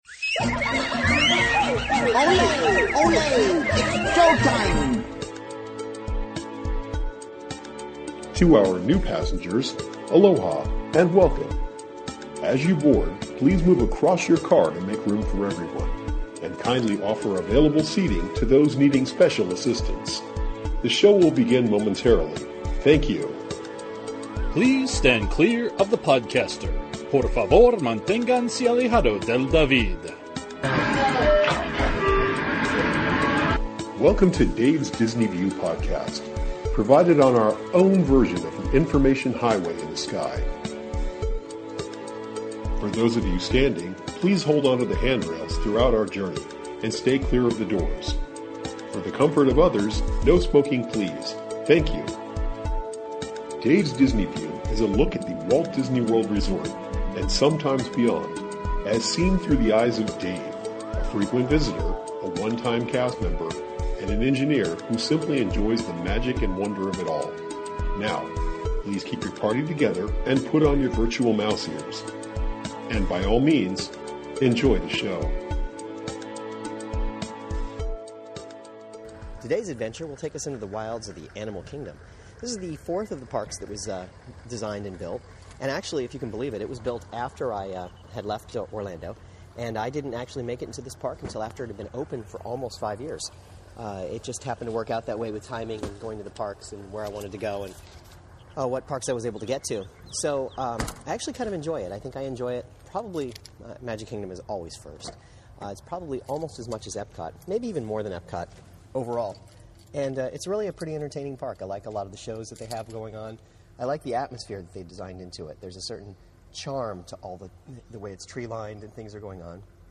This podcast is an audio exploration of the Animal Kingdom. I go through the park, and give some thoughts about what I see and experience - and I play some attraction audio for you.